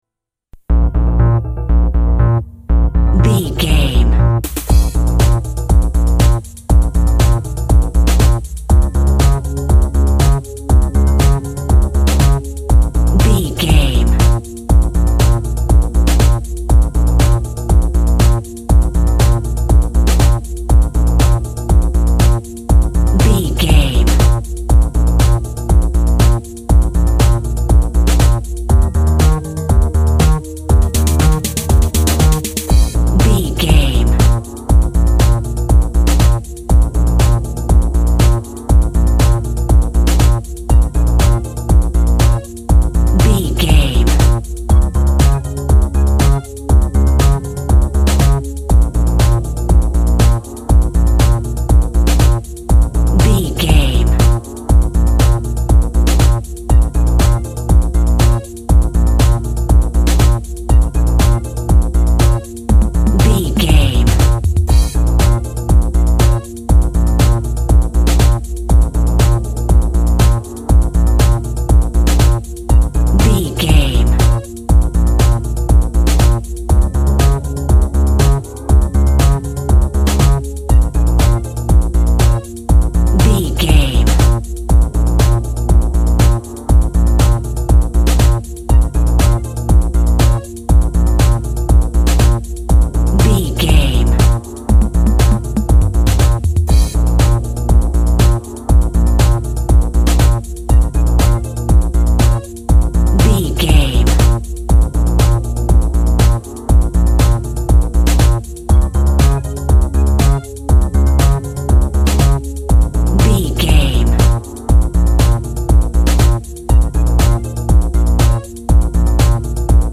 Eighties Electro Music.
Fast paced
Aeolian/Minor
B♭
dark
futuristic
synthesiser
drum machine
techno
synth lead
synth bass
Synth Pads